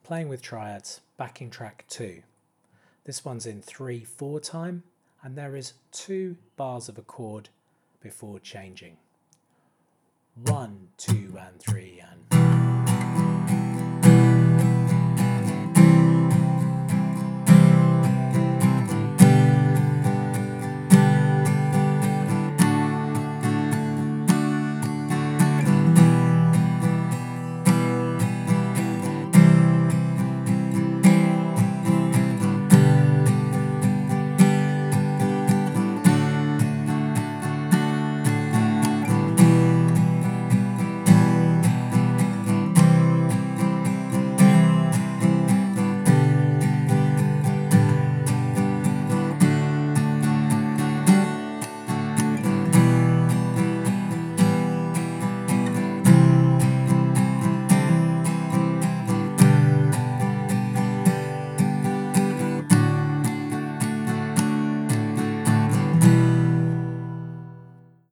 The chords in the backing track are: Em – C – G – D
This new track is in a 3/4 time.